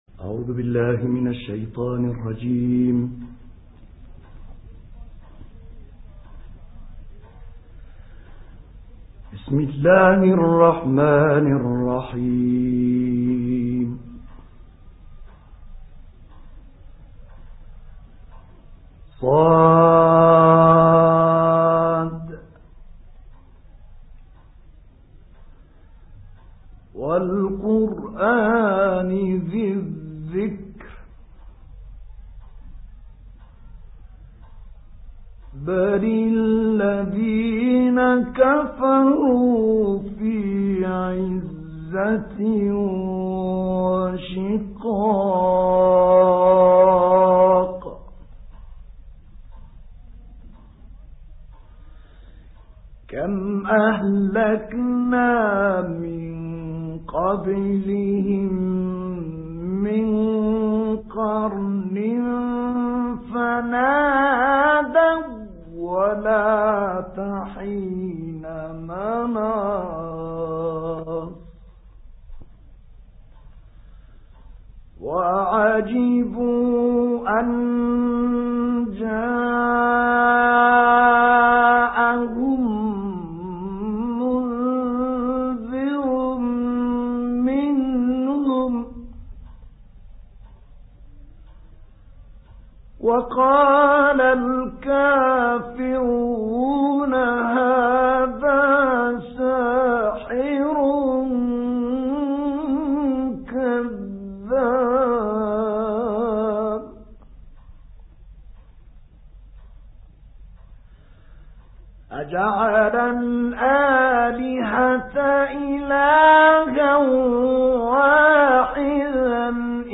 دانلود قرائت سوره ص آیات 1 تا 25 - استاد طه الفشنی
قرائت-سوره-ص-آیات-1-تا-25-استاد-طه-الفشنی.mp3